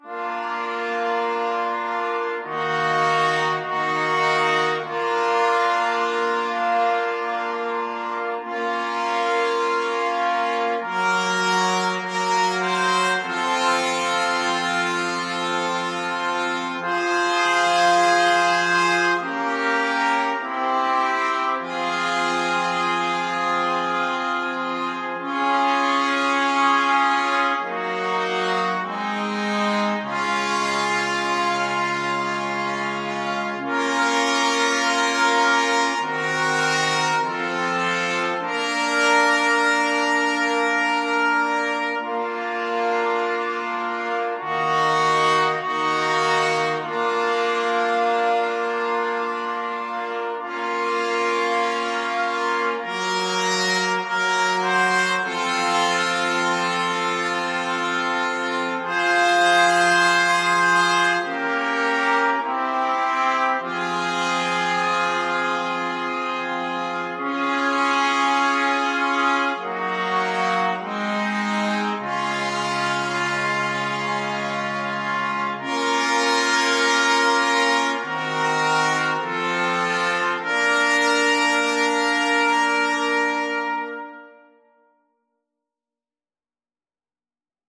Free Sheet music for Brass Quartet